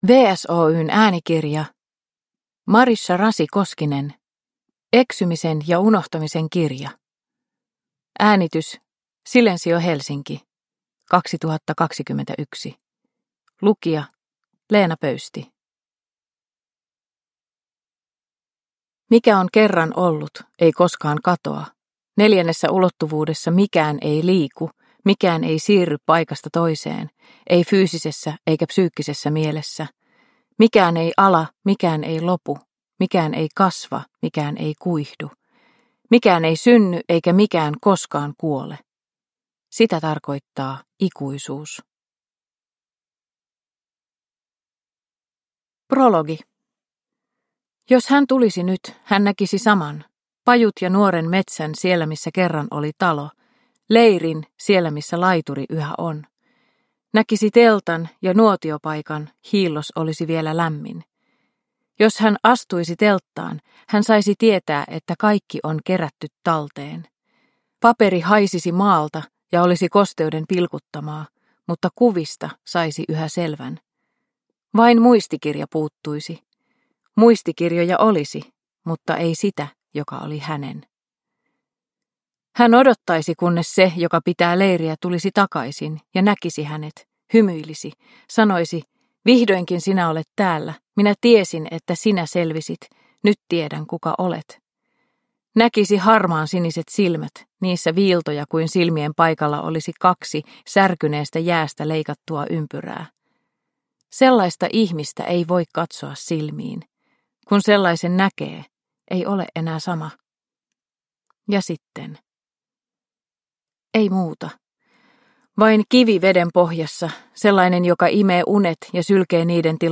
Eksymisen ja unohtamisen kirja – Ljudbok – Laddas ner